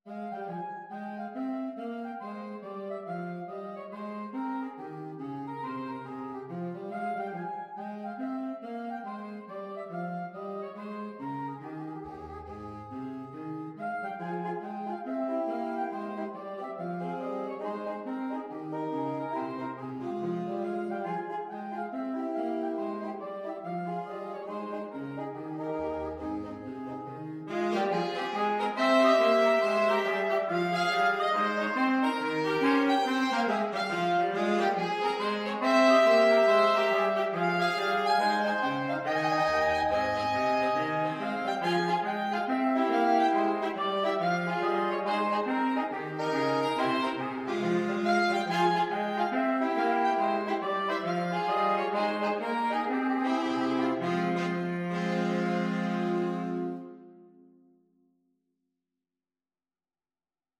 Quick Swing = c. 140
4/4 (View more 4/4 Music)
Jazz (View more Jazz Saxophone Quartet Music)